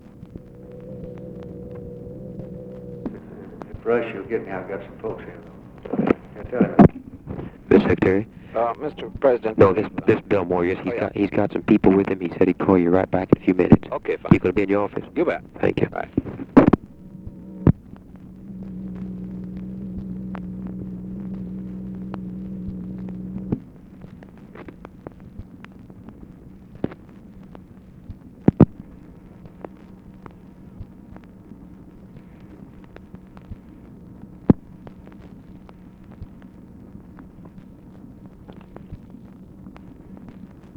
Conversation with GEORGE BALL, January 28, 1964
Secret White House Tapes